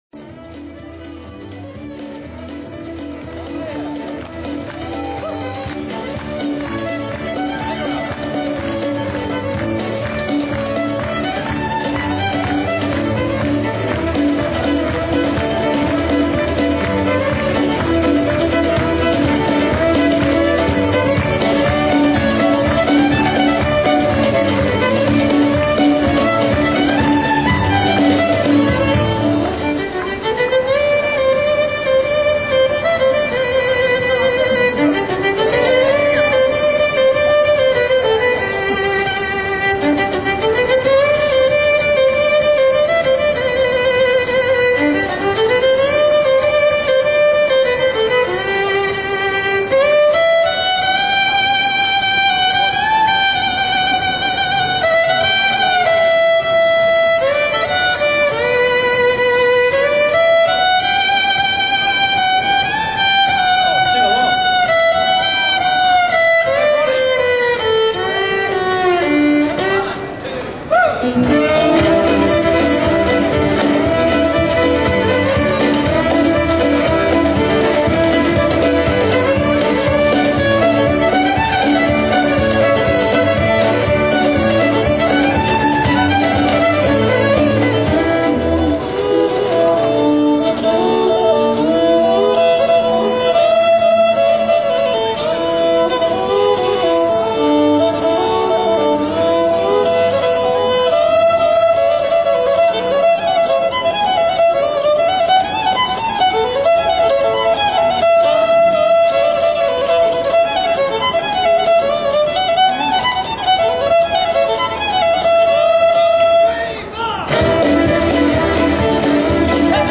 Ci spostiamo in una piccola piazza dove sono disposte parecchie sedie e sul marciapiede di fronte vedo amplificatori e microfoni.
Infatti dopo un attimo arrivano un po' di musicisti che iniziano a suonare dalla musica country a quella irlandese a pezzi di classica, ci sono sempre almeno tre o quattro violinisti accompagnati da un contrabbasso, batteria, chitarra o fisarmonica o tastiera elettronica.
Praticamente tiro sera entusiasmato dagli assoli che a turno fanno i musicisti, impegnati all'estremo nell'esecuzione dei giri più virtuosi ed impegnativi, come se fosse una gara,con un risultato a dir poco eccezionale.